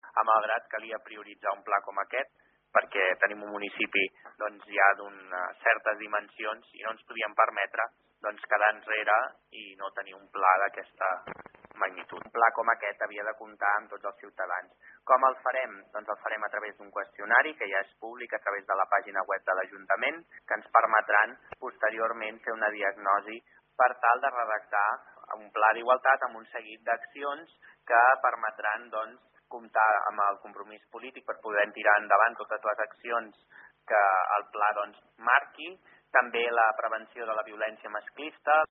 Per fer-ho han obert un procés participatiu per recollir l’opinió de la ciutadania per fer un retrat de la societat de Malgrat i detectar mancances pel que fa a la qualitat dels serveis i recursos municipals respecte a igualtat de gènere. Són declaracions de Jofre Serret,regidor d’Igualtat de Malgrat de Mar, que apunta a la urgència del pla en col·laboració amb la ciutadania.